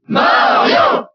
Category:Crowd cheers (SSBB) You cannot overwrite this file.
Mario_Cheer_French_SSBB.ogg.mp3